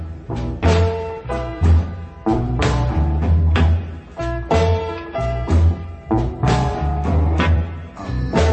blues_blues.00003.mp3